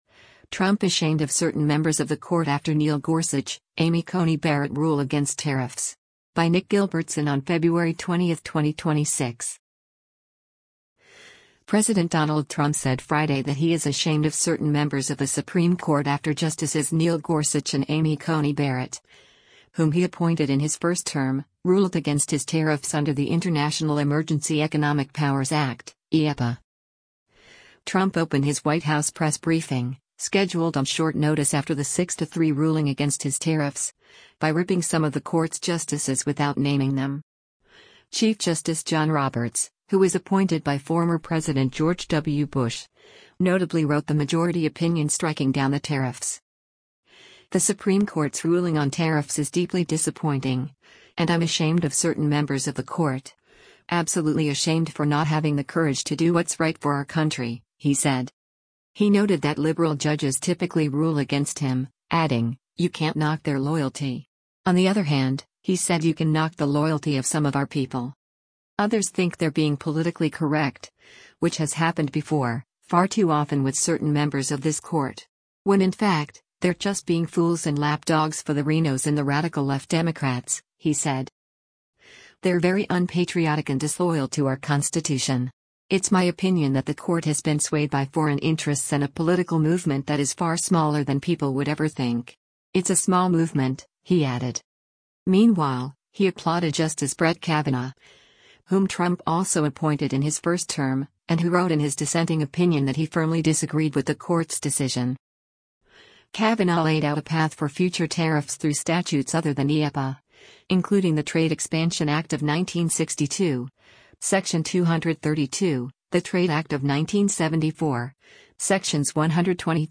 US President Donald Trump speaks during a press conference at the White House, Washington,